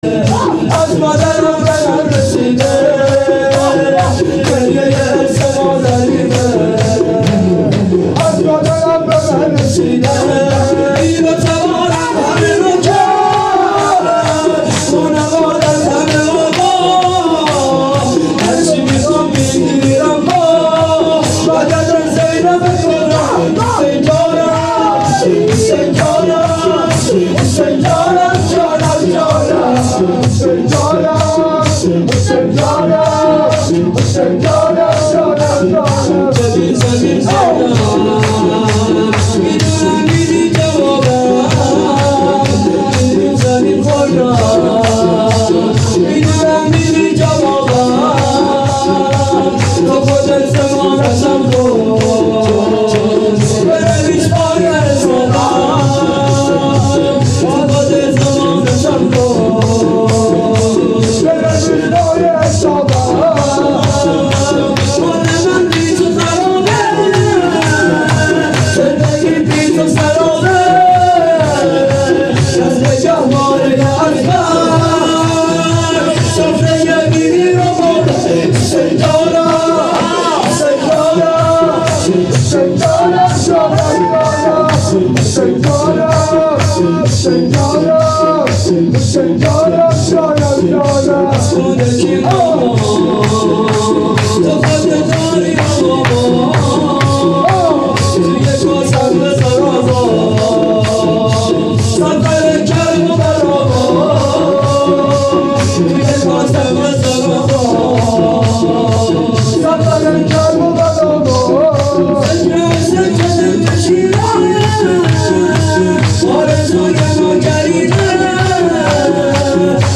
مراسم هفتگی۹۳/۱۱/۱۵